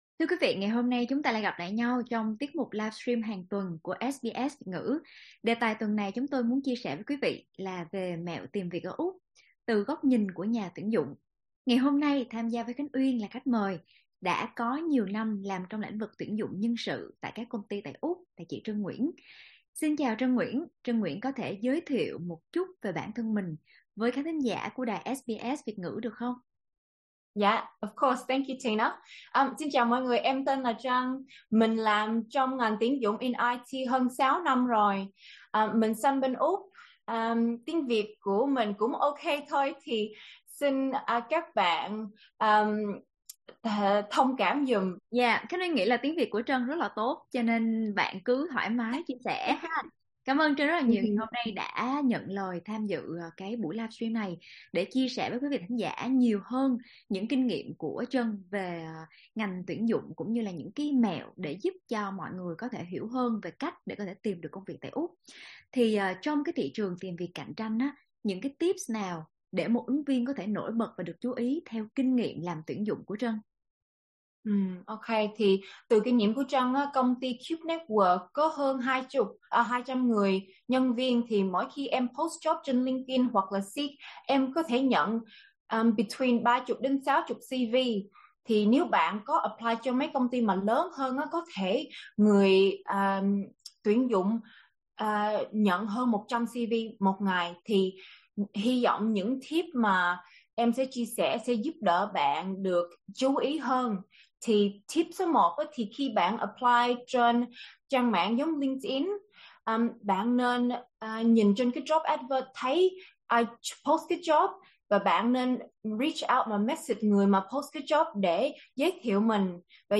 Cùng nghe chia sẻ từ chính nhà tuyển dụng tại Úc.